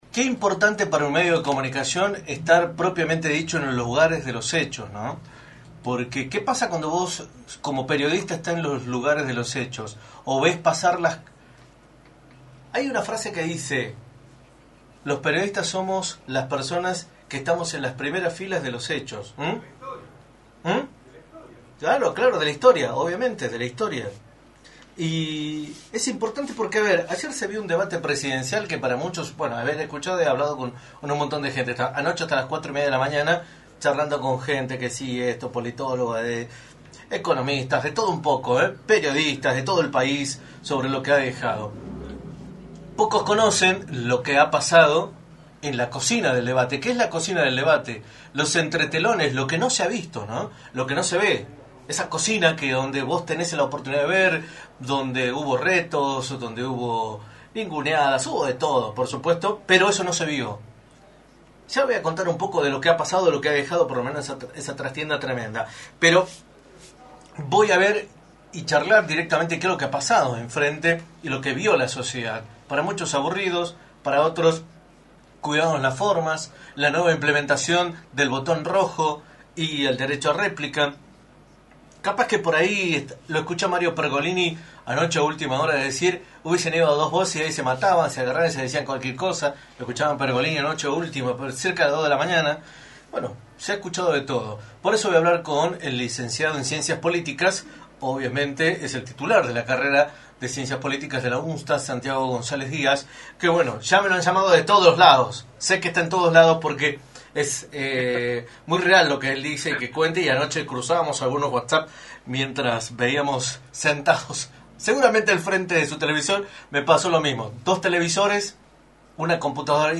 en entrevista para “La Mañana del Plata”, por la 93.9.